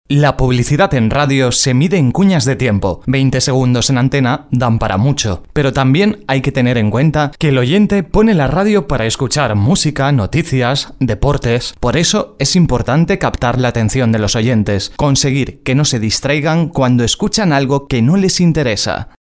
Sprechprobe: eLearning (Muttersprache):
Durante 25 años en el mundo de la radio mi voz ha pasado por tooodos los registros que te puedas imaginar, los más serios, divertidos, tensos, tontos... todo ello plasmado en cuñas de radio, spots de Tv, programas musicales, de noticias, como reportero en unidad móvil, en presentación de eventos y charlas, en definitiva una voz versátil preparada para ser modulada y adaptada a cualquier trabajo